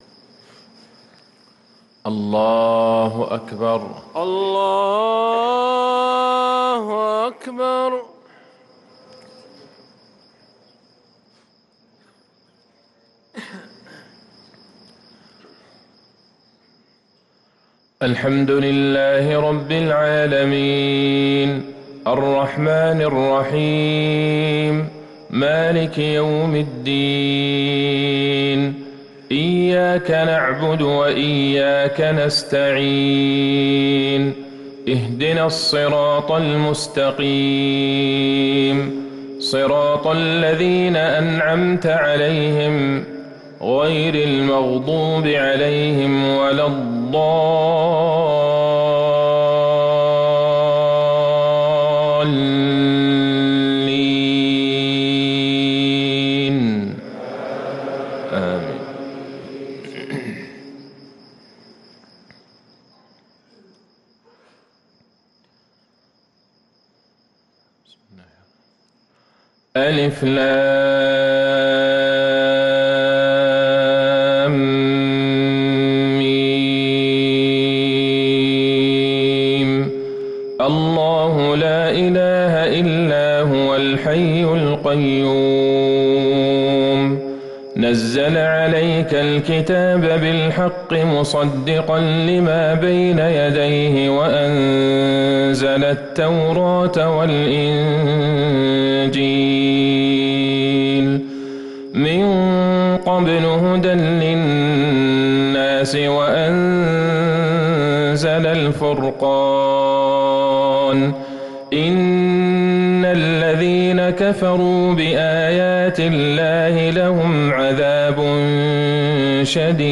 صلاة الفجر للقارئ عبدالله البعيجان 24 شوال 1444 هـ